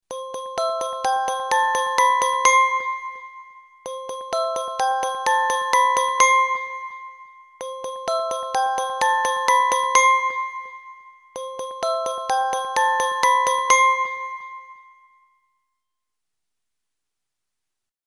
Soundeffekte